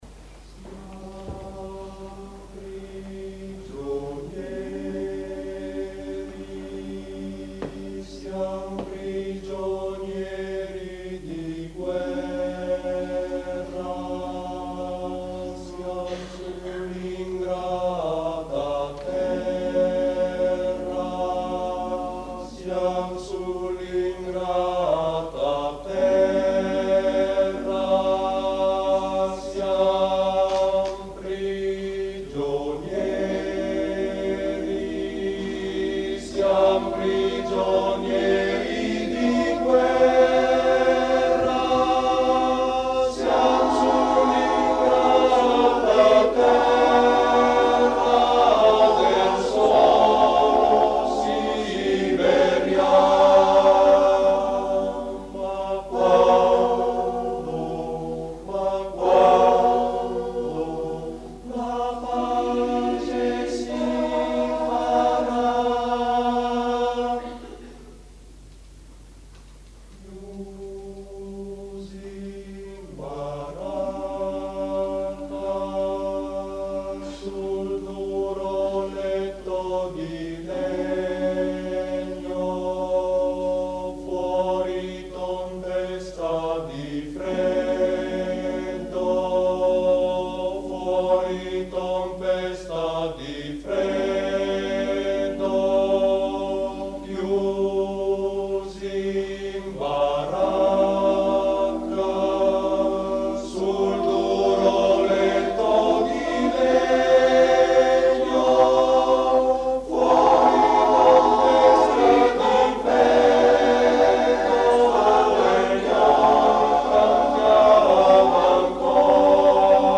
[ voci virili ]